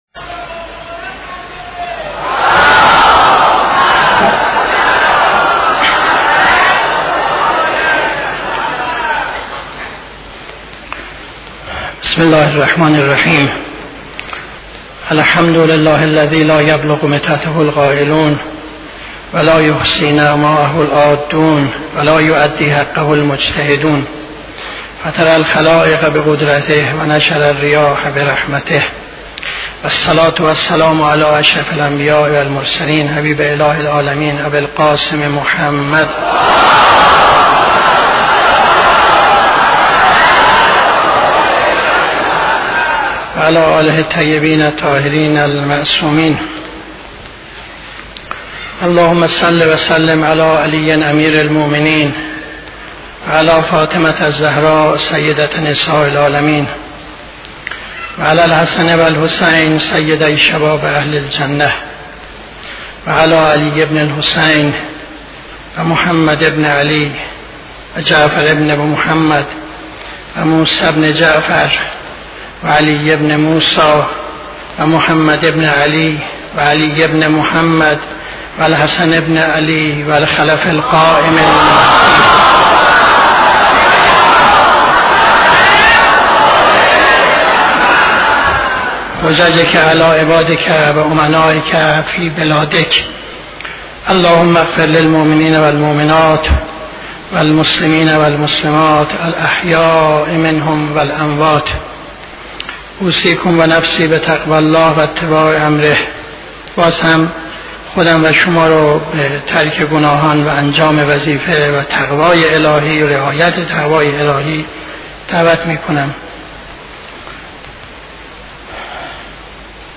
خطبه دوم نماز جمعه 18-12-74